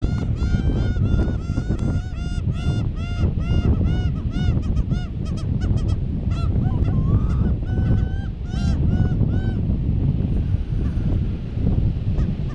Olrog´s Gull (Larus atlanticus)
Life Stage: Adult
Country: Argentina
Location or protected area: Camarones
Condition: Wild
Certainty: Observed, Recorded vocal